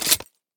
select-shotgun-1.ogg